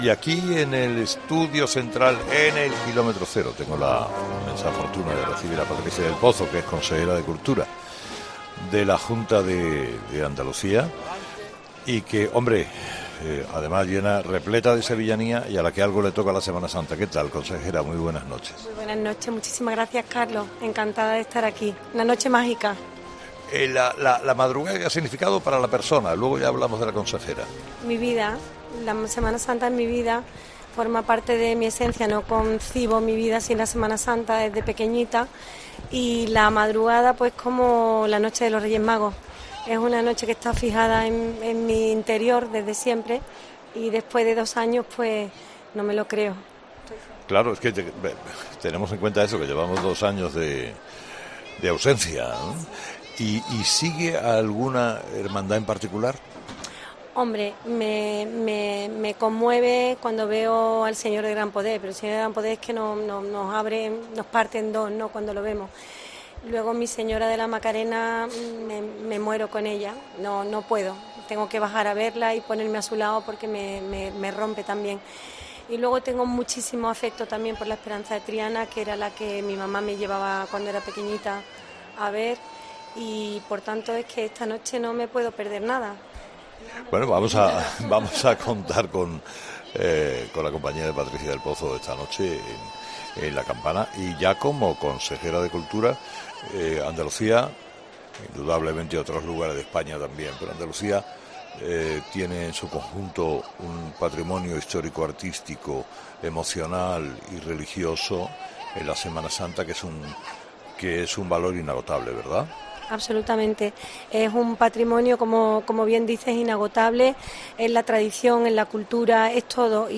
Carlos Herrera ha tenido la oportunidad de charlar con la responsable de Cultura de la Junta de Andalucía durante 'La Madrugá' de Sevilla